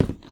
footstep-tile.wav